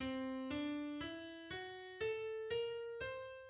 Prometheus scale
It may be thought of as C Lydian dominant without the 5th degree.